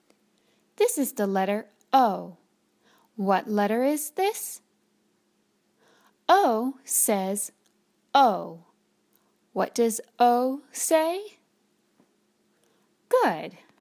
Long O